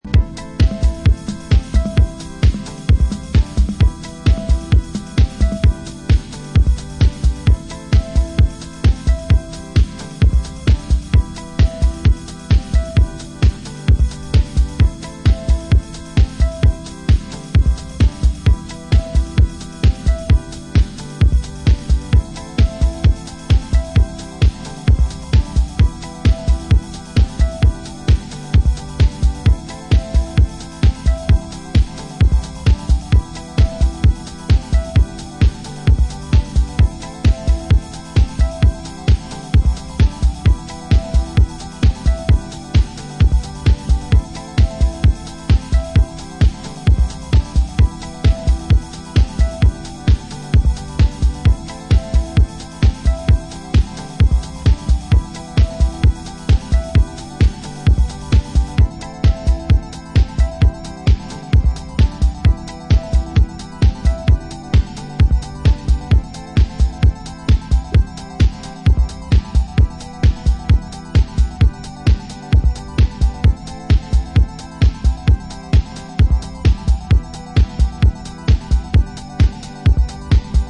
シカゴ・ ハウス注目の次世代気鋭プロデューサー